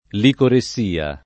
licoressia [ likore SS& a ]